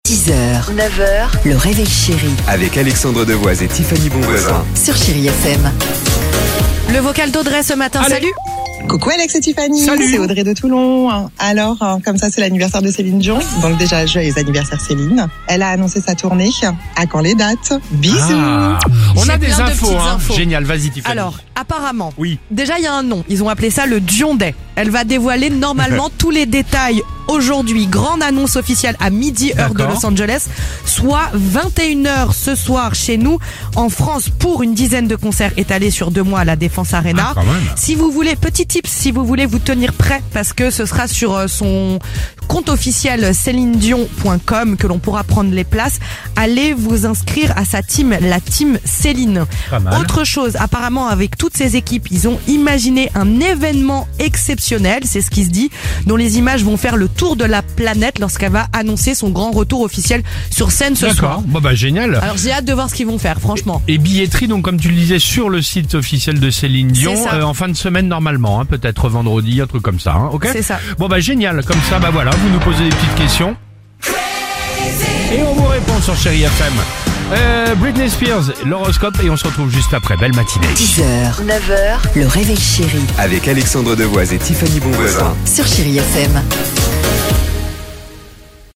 Tous les matins à 6h35 on écoute vos messages laissés sur l'insta et FB du réveil Chérie !